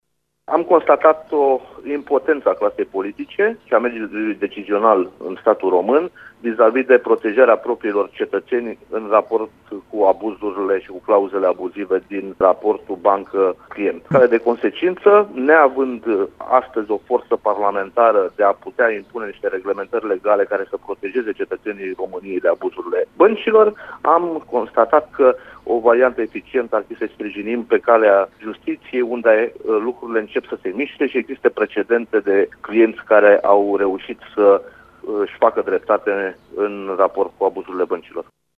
Preşedintele formaţiunii, Bogdan Diaconu, a declarat pentru RTM că acţiunea a fost demarată deoarece românii care suferă de pe urma clauzelor abuzive din contractele cu băncile își pot găsi dreptatea numai în justiţie: